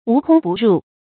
無空不入 注音： ㄨˊ ㄎㄨㄙ ㄅㄨˋ ㄖㄨˋ 讀音讀法： 意思解釋： 見「無孔不入」。